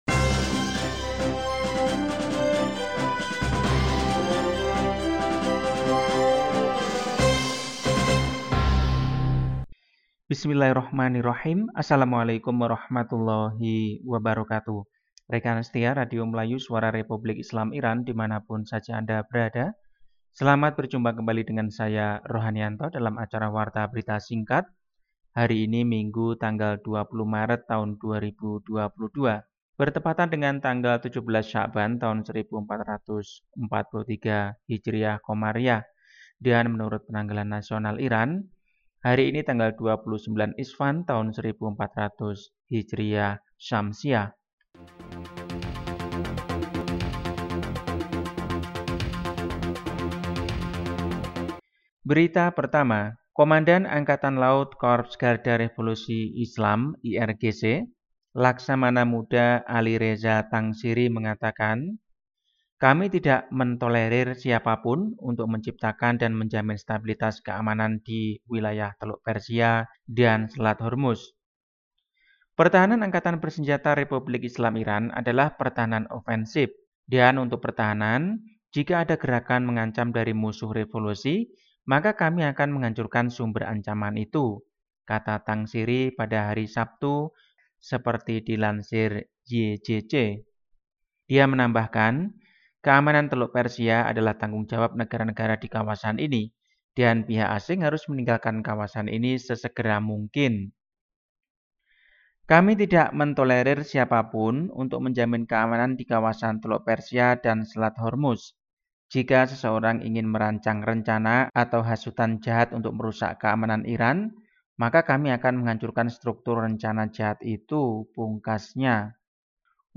Warta berita hari ini, Minggu, 20 Maret 2022.